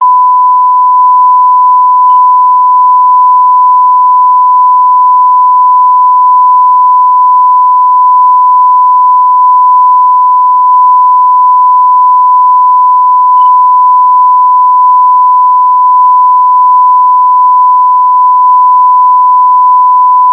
1_1kHz_0dB.amr